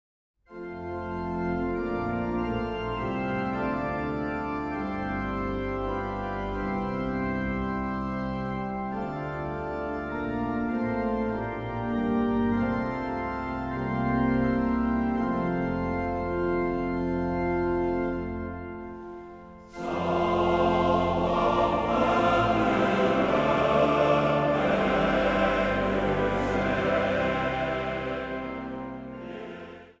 psalmen en gezangen